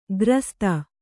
♪ grasta